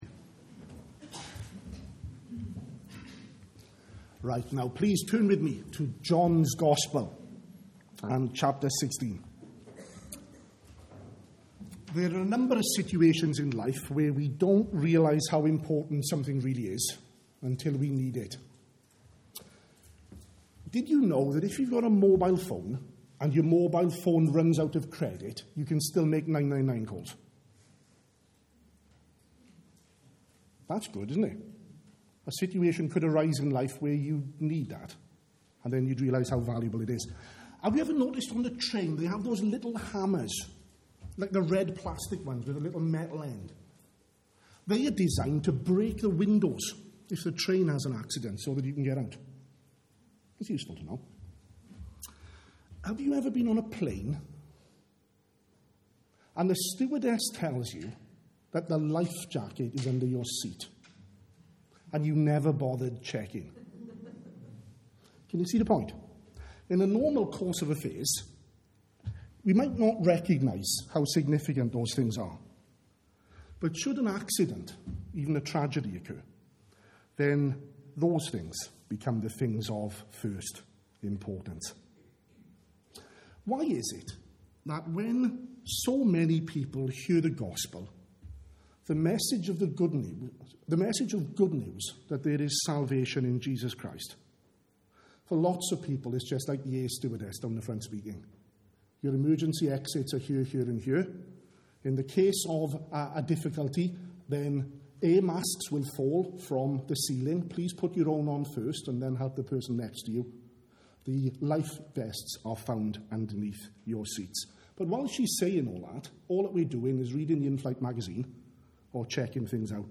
at the morning service